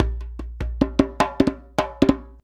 100DJEMB14.wav